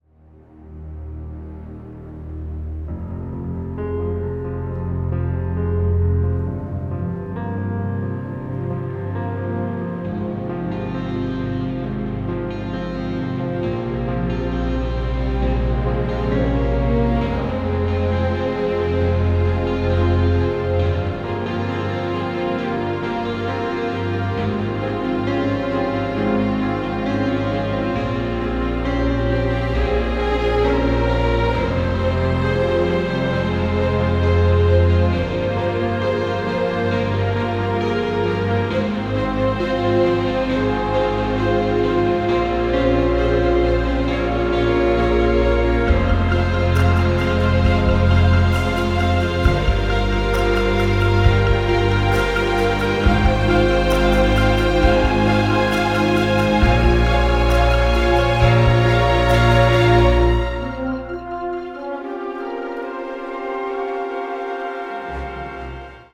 delivers a poignant and deeply moving symphonic score